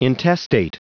Prononciation du mot intestate en anglais (fichier audio)